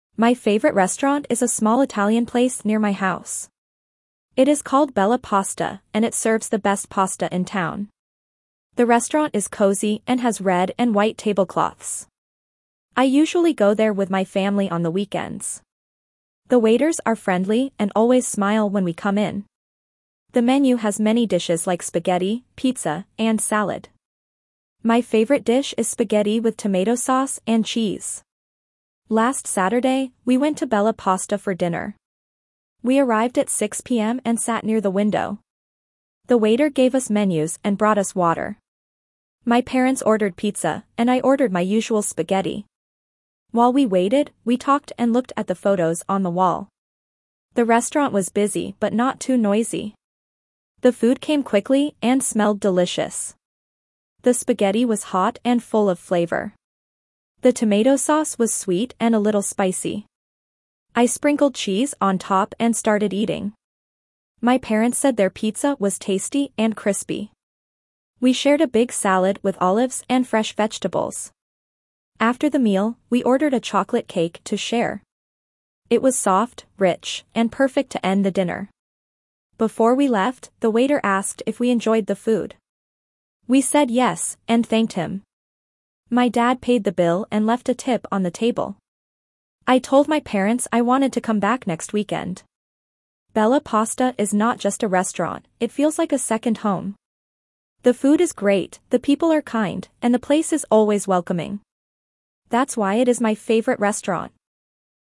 Reading A2 - My Favorite Restaurant
2.-A2-Reading-My-Favorite-Restaurant.mp3